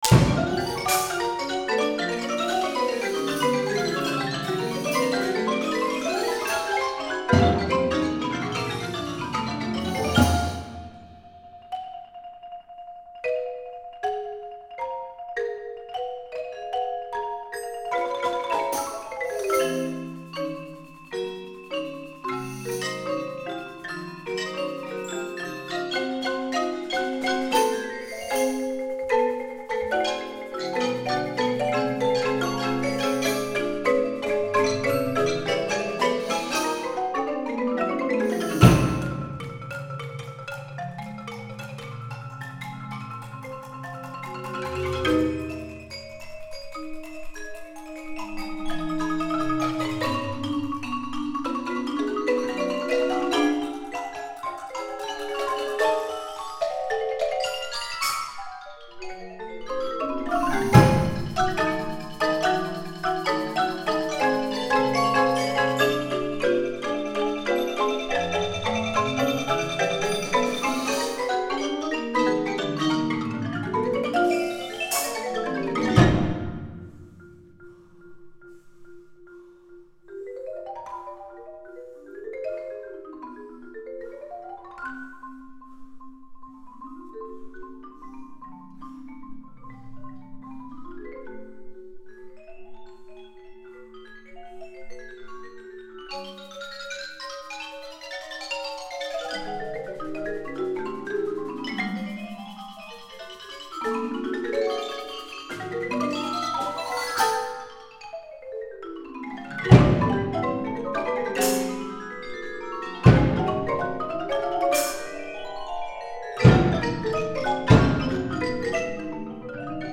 Genre: Percussion Ensemble
# of Players: 12
Xylophone 1
Vibraphone 1
Marimba 1
(Starting Gun or Slapstick, Vibraslap, Glockenspiel, Guiro)
(Tambourine, Vibraslap, Splash Cymbal)